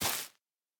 Minecraft Version Minecraft Version 1.21.5 Latest Release | Latest Snapshot 1.21.5 / assets / minecraft / sounds / block / sponge / step5.ogg Compare With Compare With Latest Release | Latest Snapshot
step5.ogg